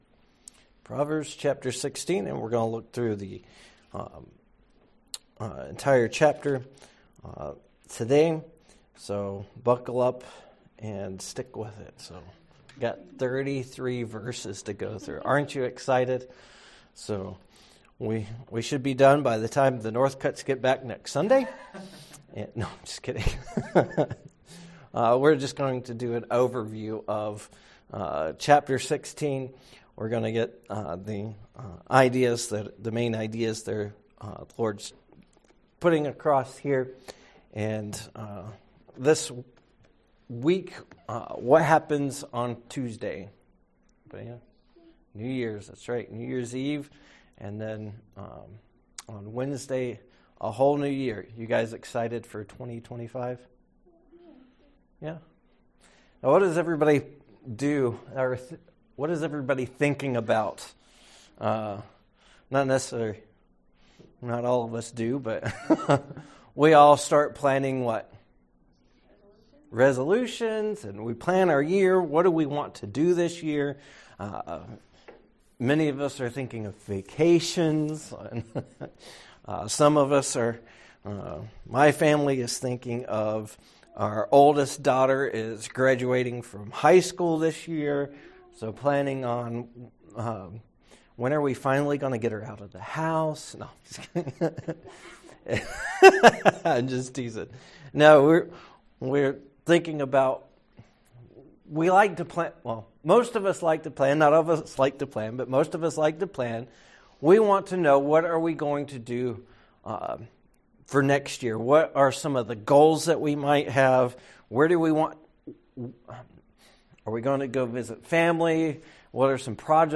Sermons: Preparing for A New Year